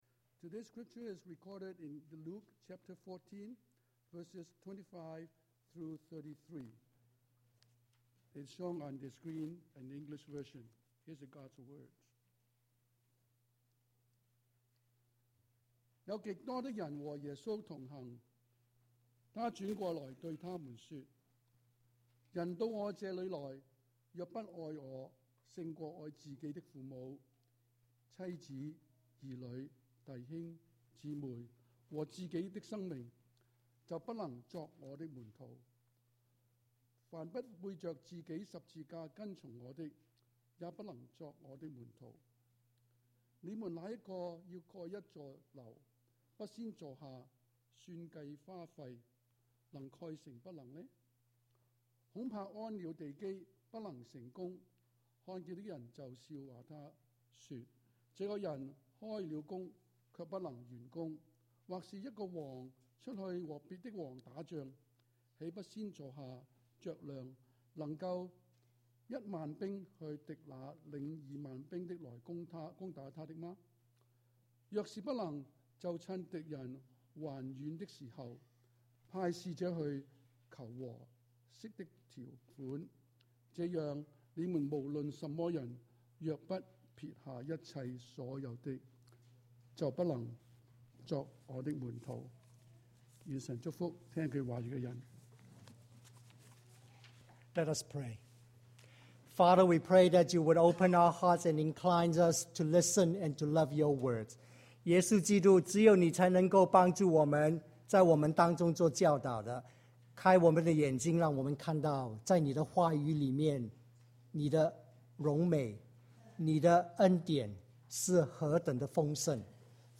Pricey Discipleship: March 7, 2010 Sermon